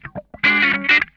CRUNCHWAH 6.wav